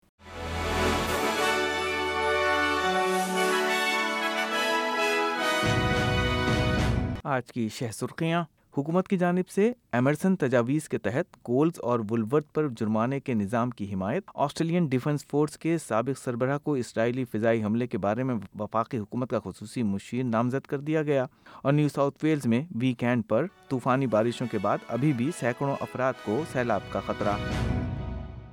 نیوز فلیش: 8 اپریل 2024: آسٹریلین ڈیفنس فورس کے سابق سربراہ اسرائیلی حملوں پر وفاقی حکومت کے خصوصی مشیر نامزد